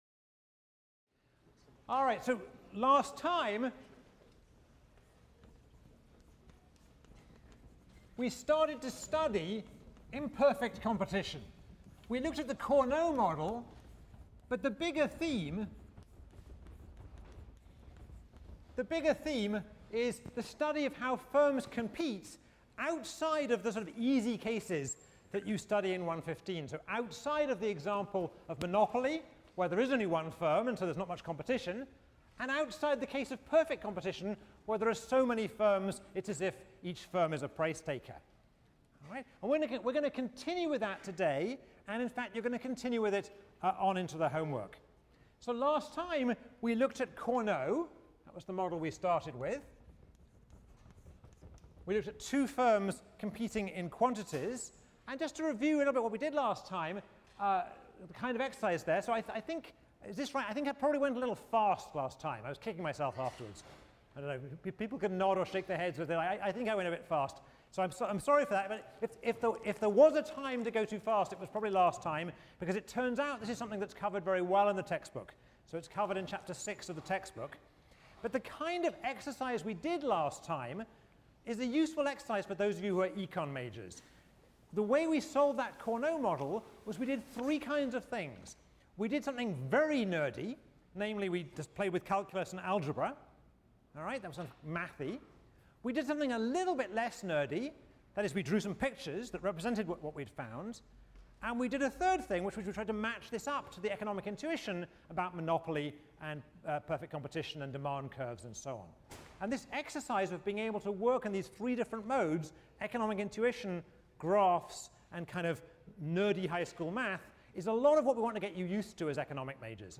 ECON 159 - Lecture 7 - Nash Equilibrium: Shopping, Standing and Voting on a Line | Open Yale Courses